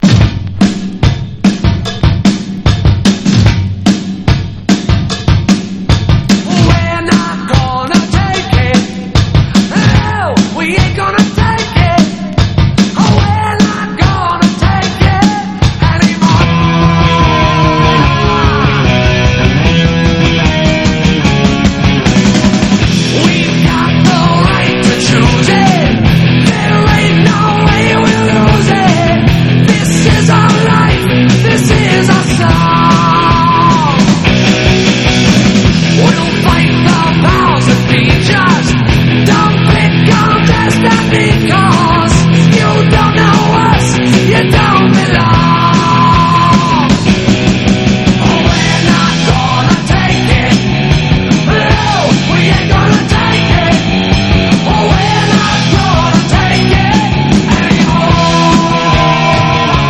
ROCKABILLY / ROCK & ROLL / OLDIES